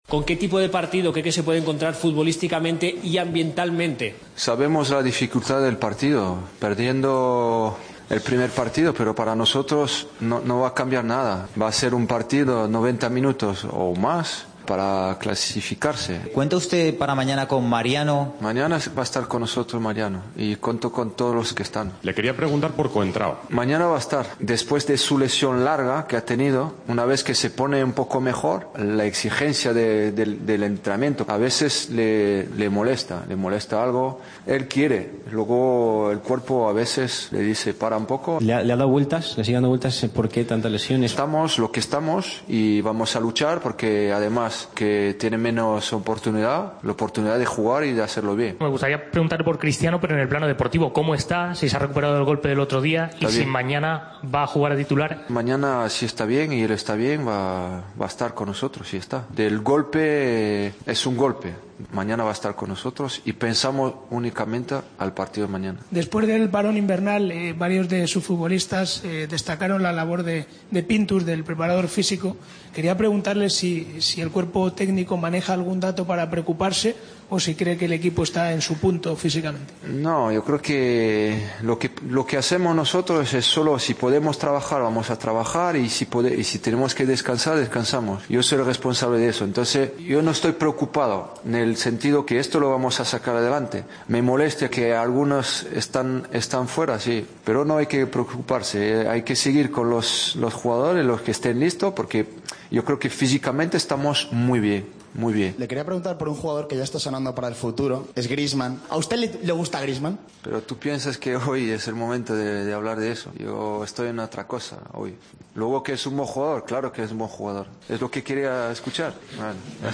AUDIO: Escucha los mejores momentos de la rueda de prensa de Zidane, el día previo a la vuelta de cuartos frente al Celta.